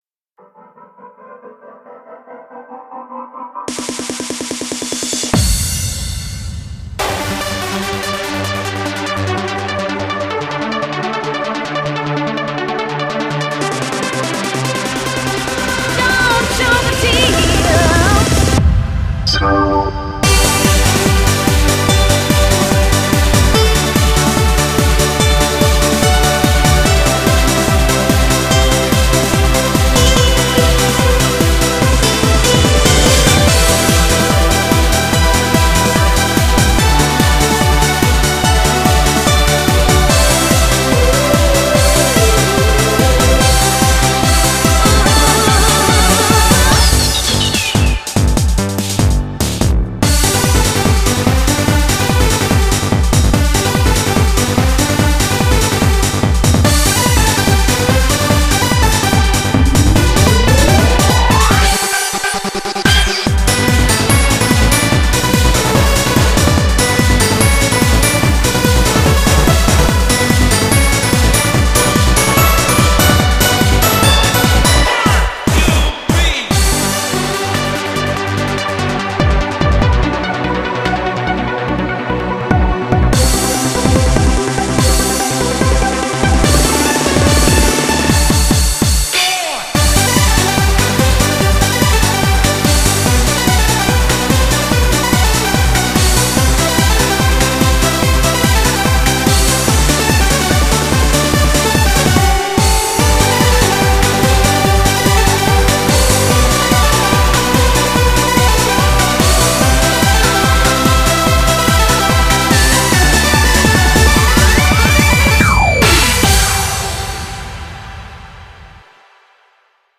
BPM145
Comments[TRANCE]